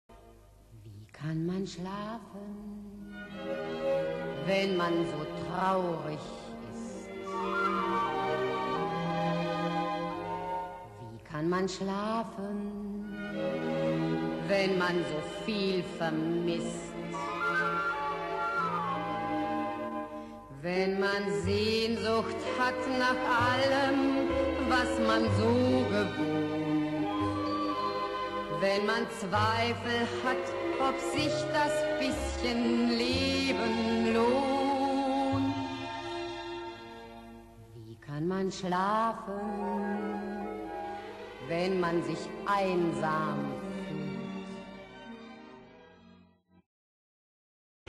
Musikalisches Lustspiel in sechs Bildern